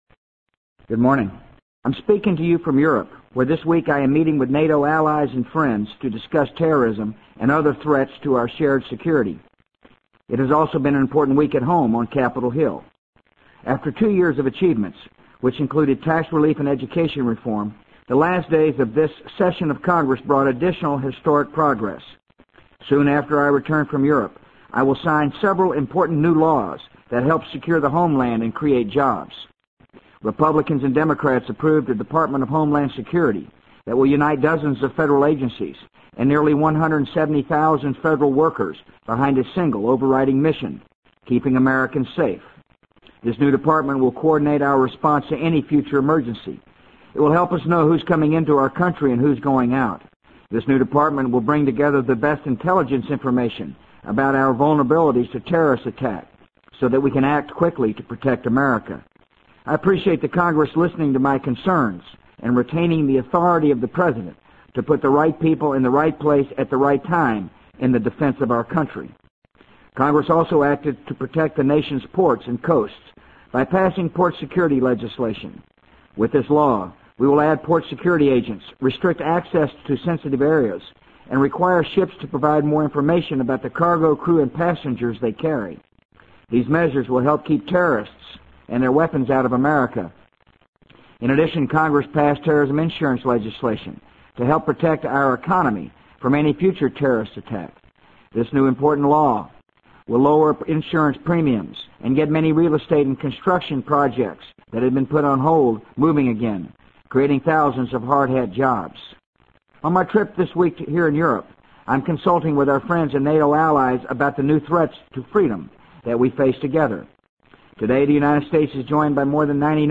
【美国总统George W. Bush电台演讲】2002-11-23 听力文件下载—在线英语听力室